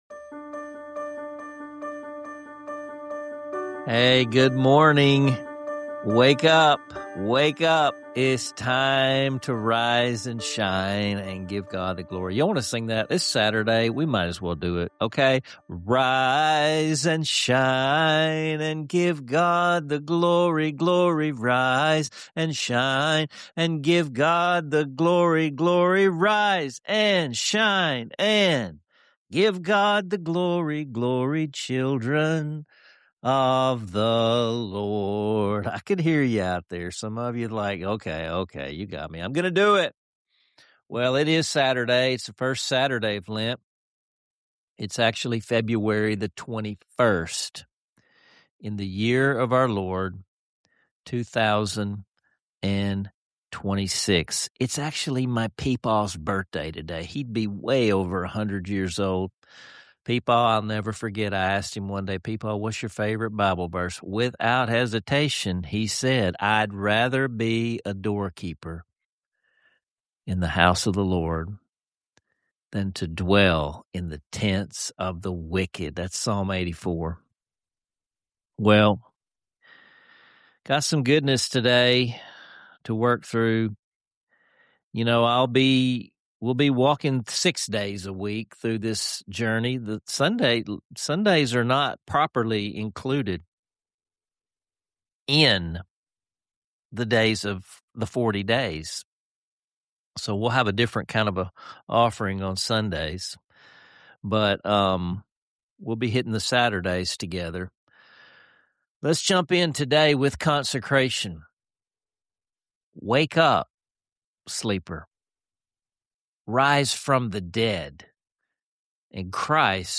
An inspiring live rendition of “Nearer My God to Thee,” bringing a sense of worship and togetherness to the episode
00:14 Joyful Wake-Up Song Kicks Off Lent